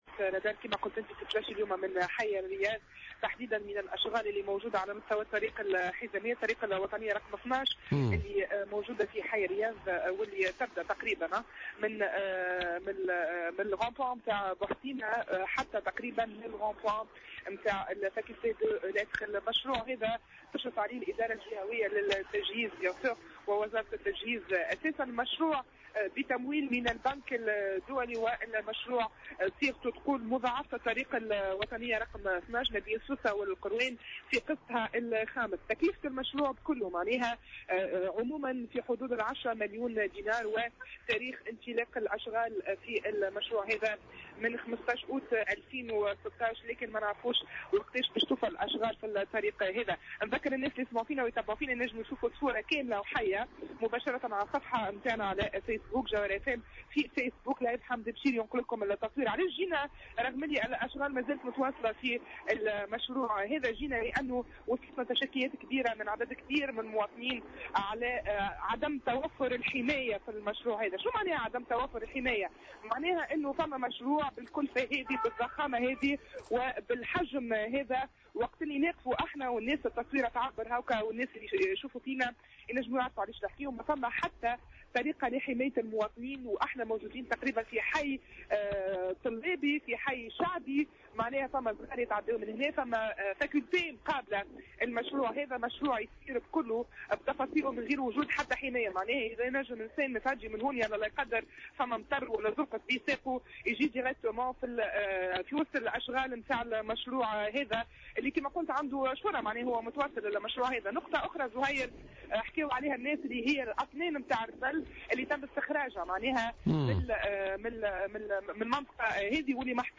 تحوّل فريق فقرة "الرادار" اليوم الأربعاء، إلى حي الرياض من ولاية سوسة وتحديدا لمعاينة أشغال مضاعفة الطريق الطريق الوطنية رقم 12 الرابطة بين سوسة والقيروان .